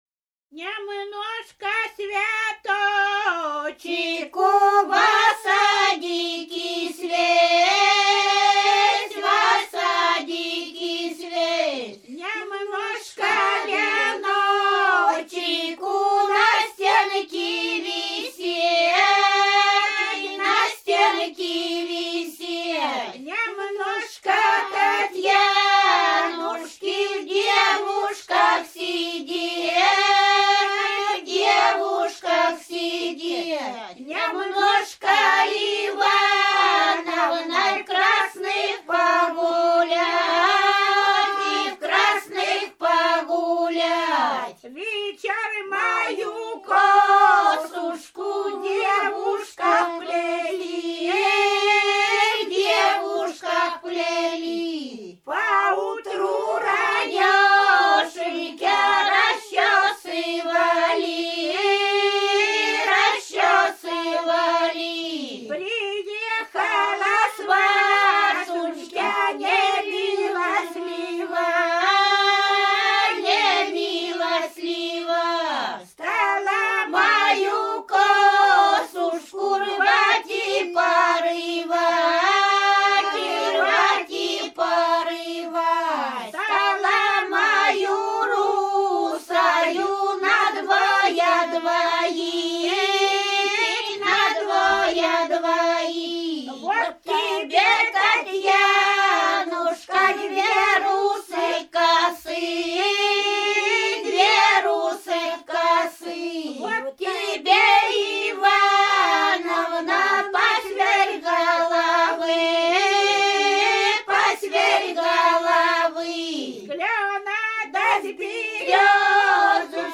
Рязань Кутуково «Нямножко цвяточику», свадебная.